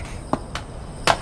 Wrath's Footsteps_2.wav